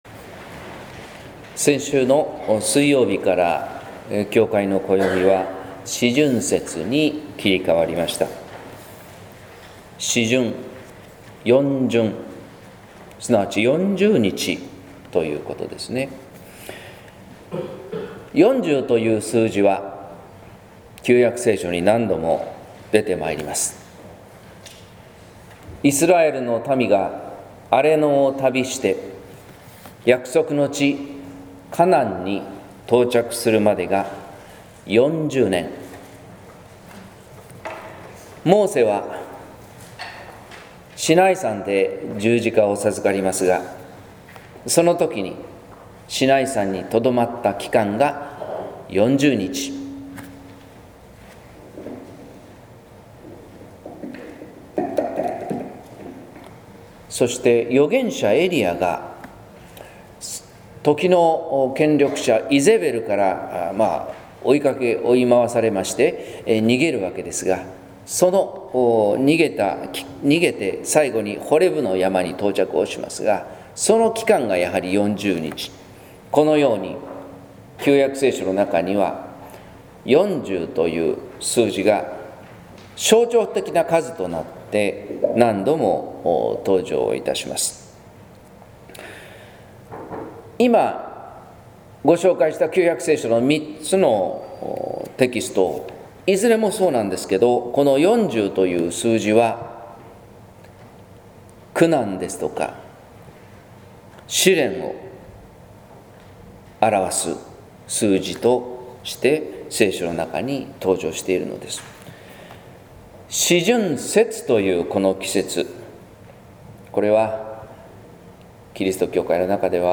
説教「朽ちる肉とともに」（音声版）
四旬節第１主日（2015年2月22日）